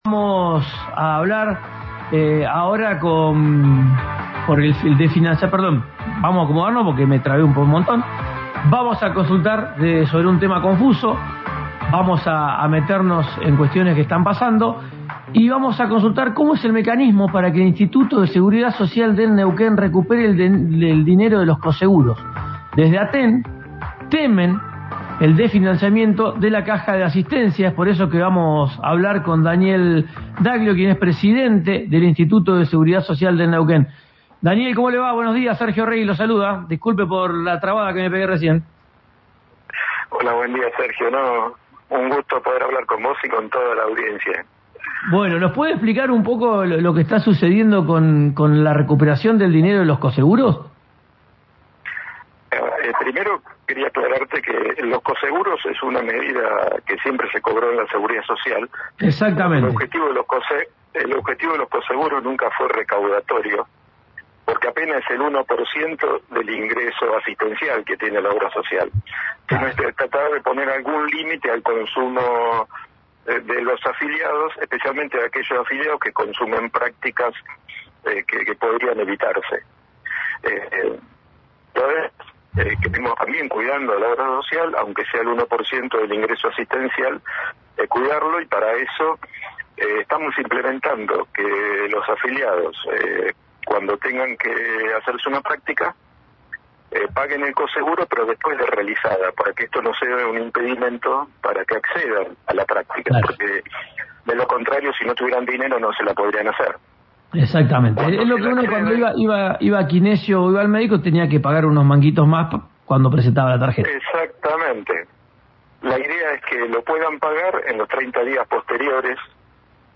Escuchá a Daniel Daglio, administrador del ISSN, en RÍO NEGRO RADIO:
En diálogo con RÍO NEGRO RADIO le contestó a ATE por la preocupación que habían planteado respecto de los coseguros aún no recuperados por la obra social, tras el cambio que se implementó en febrero.